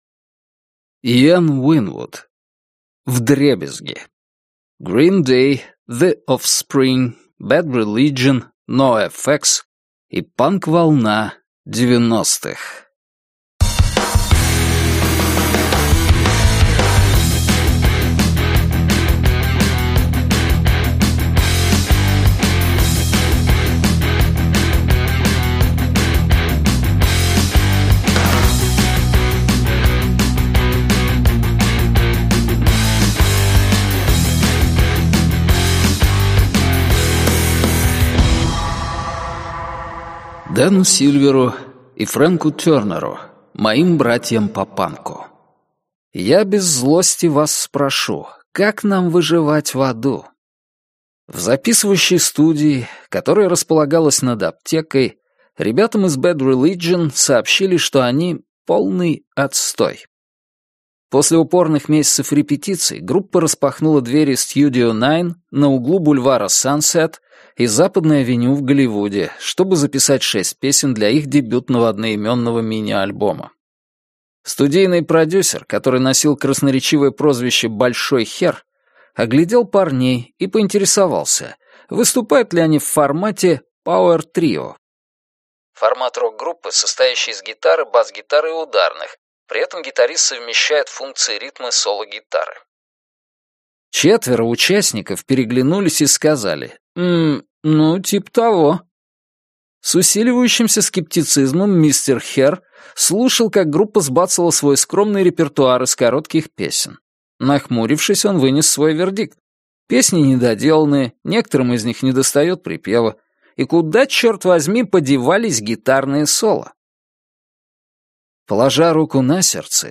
Аудиокнига Вдребезги: GREEN DAY, THE OFFSPRING, BAD RELIGION, NOFX и панк-волна 90-х | Библиотека аудиокниг